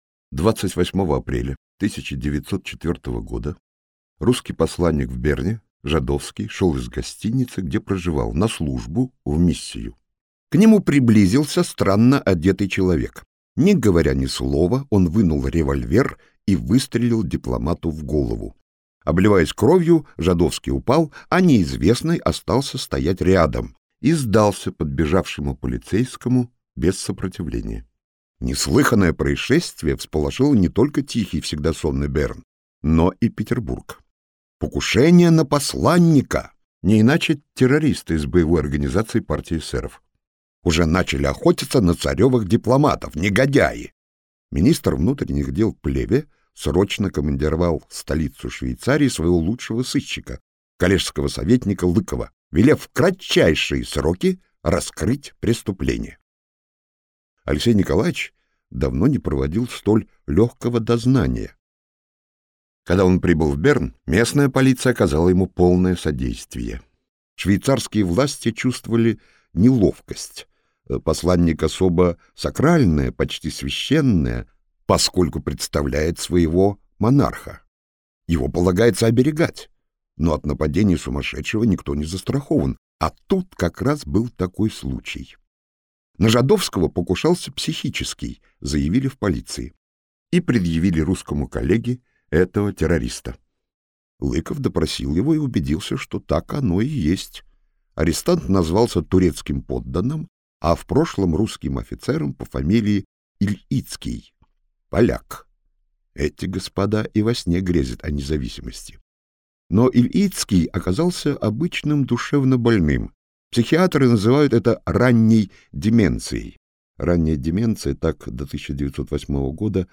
Аудиокнига Тифлис 1904 - купить, скачать и слушать онлайн | КнигоПоиск